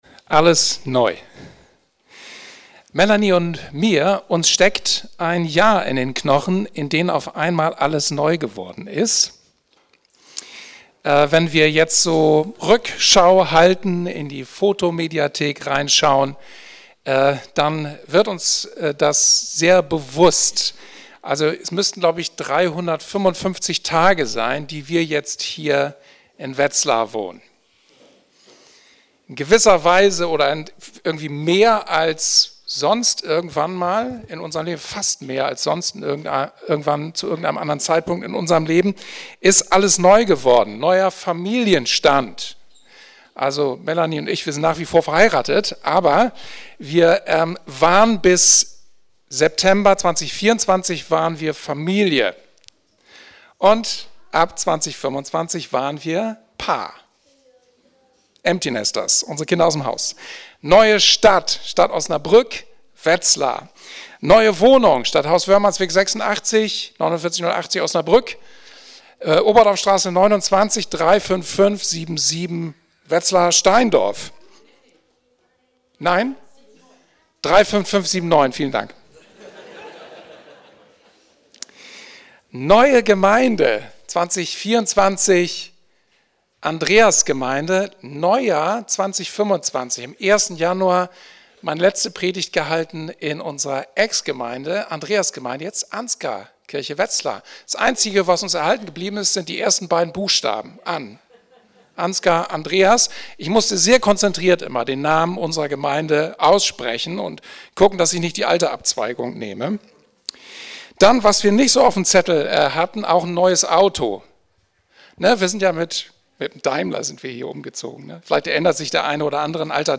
Alles Neu ~ Anskar Wetzlar // Predigt Podcast